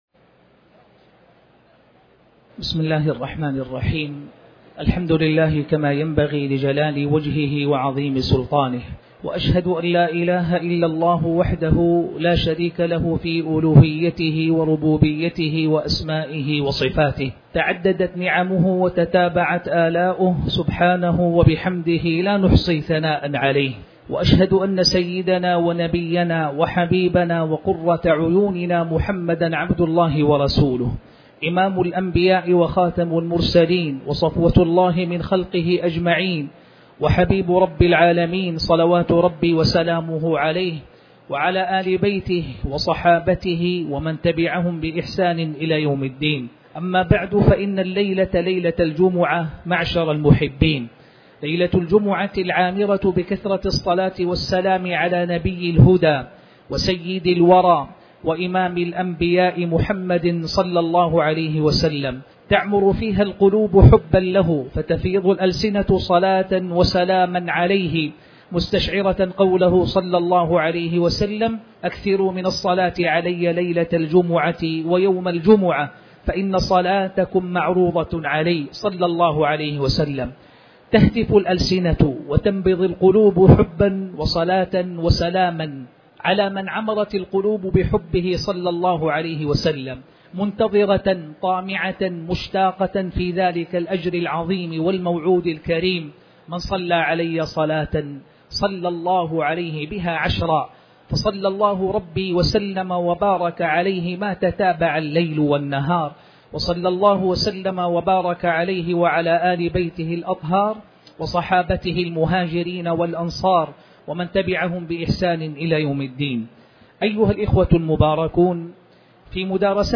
تاريخ النشر ٣٠ صفر ١٤٤٠ هـ المكان: المسجد الحرام الشيخ